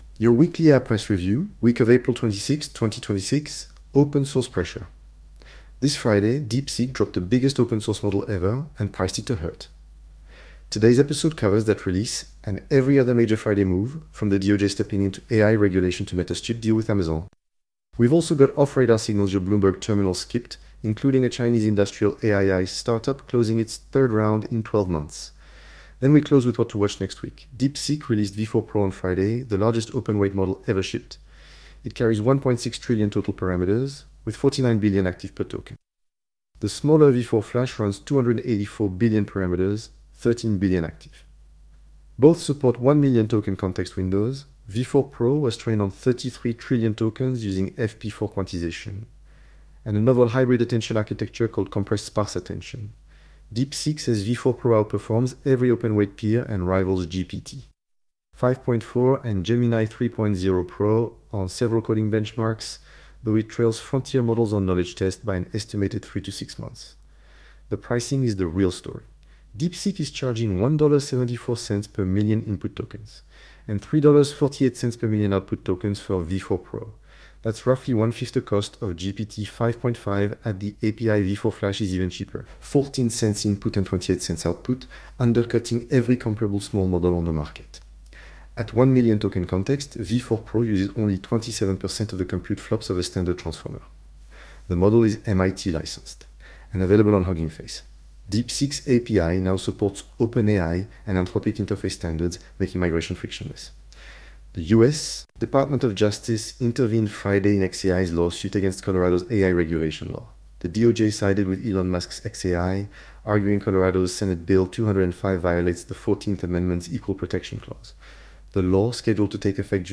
Synthetic in voice, genuine in substance. Now presented by an AI clone of your devoted host. And yes, the accent is... how you say... more French than the man himself.